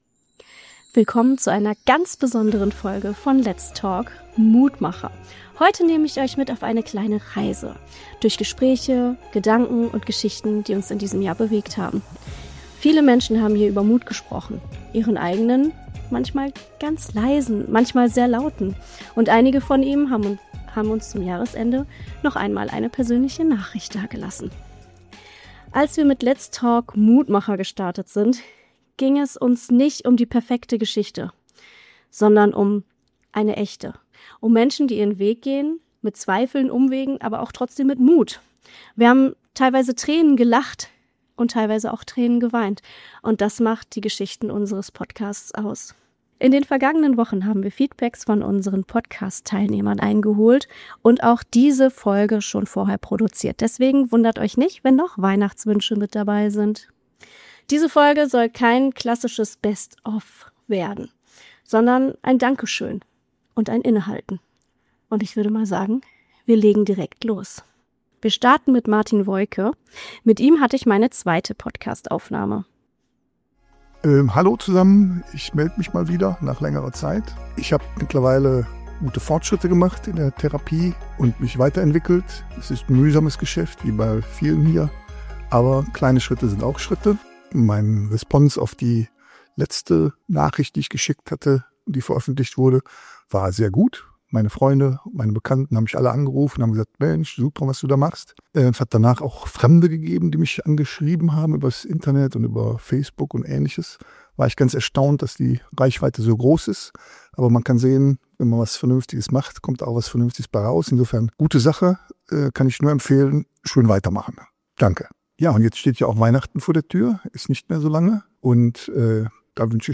Ehemalige Gäste melden sich zurück und erzählen, wie es ihnen heute geht, was sich seit ihrer Folge verändert hat und was sie aus ihren Erfahrungen mitnehmen.